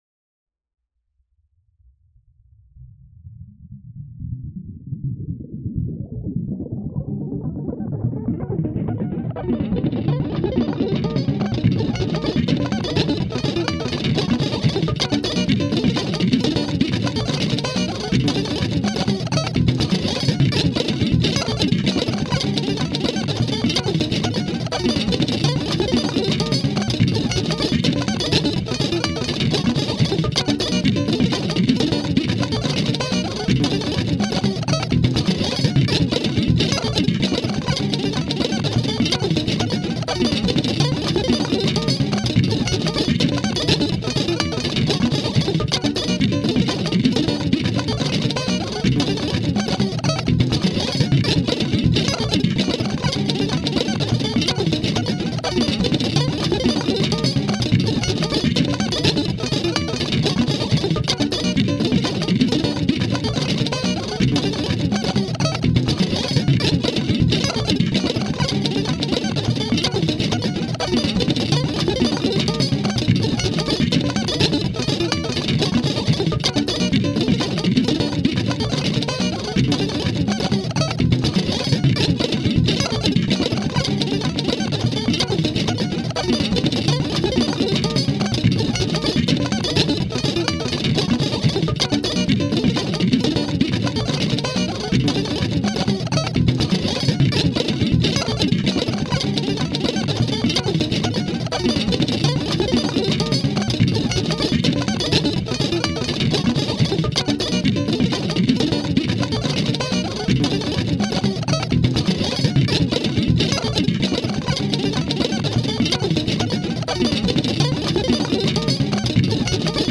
呪術的、儀式的な、プレクティクス・パルス・ミュージック。
パルスによる超感覚的な酩酊感。
パルスにおけるプレクティクス・ギター・オーケストレーション。